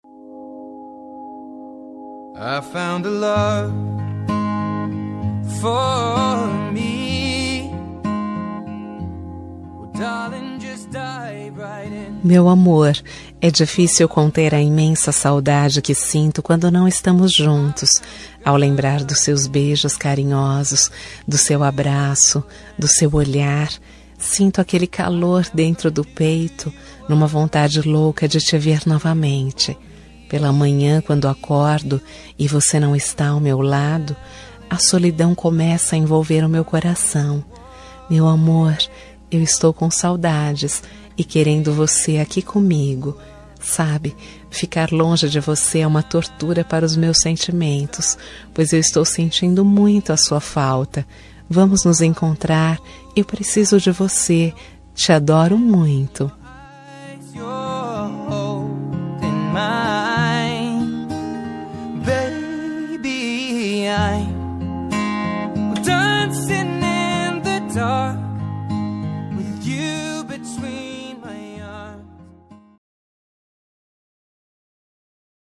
Telemensagem Romântica Distante – Voz Feminino – Cód: 201895